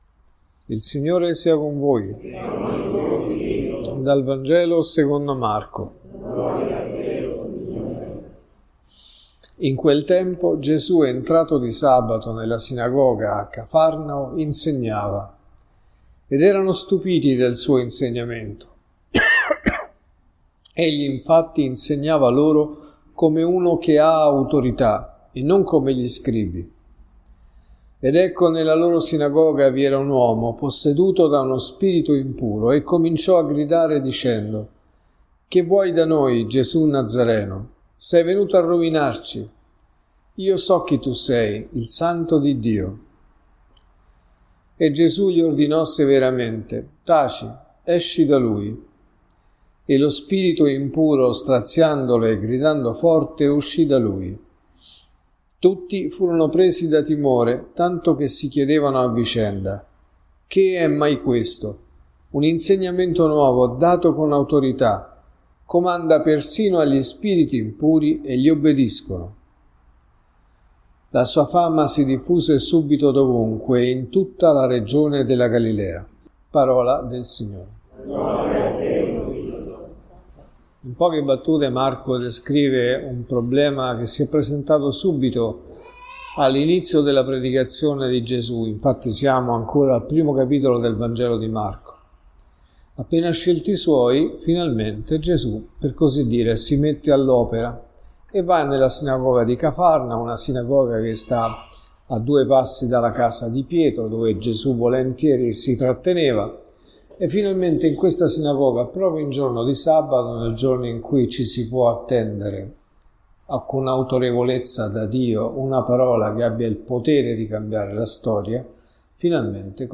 Omelie